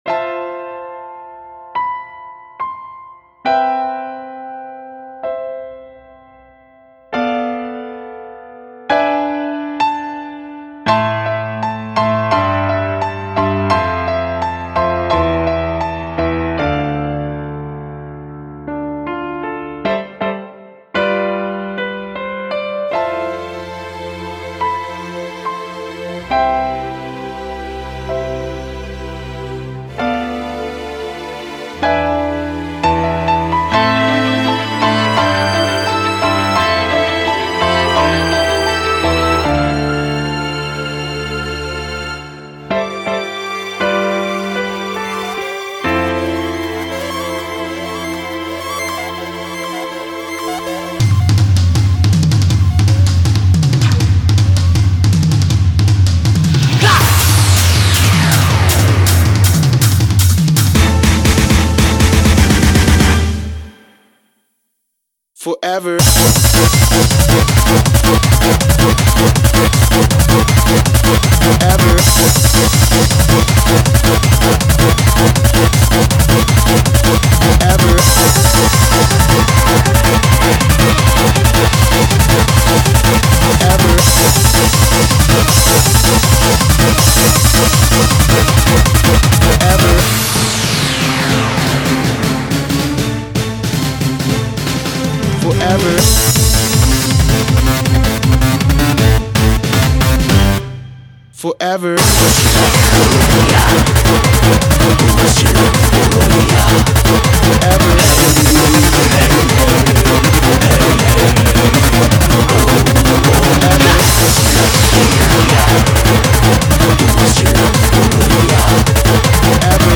BPM57-170